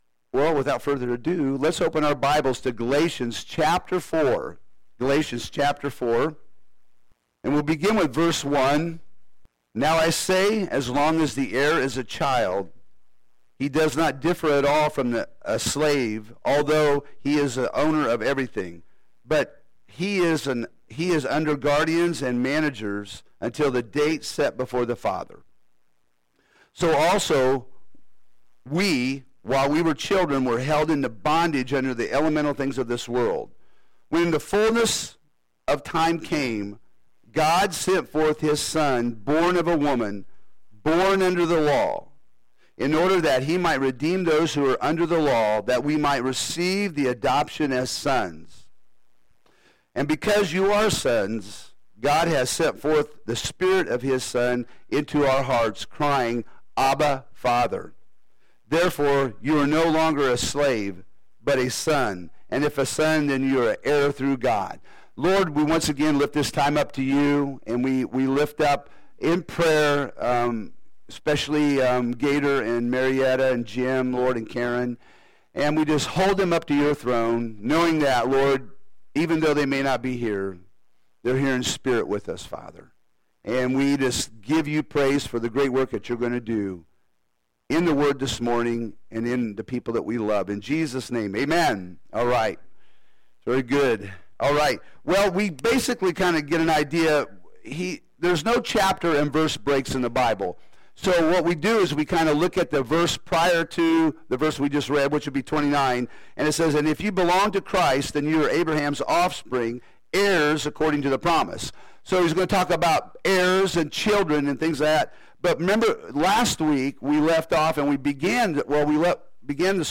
Home › Sermons › Galatians 4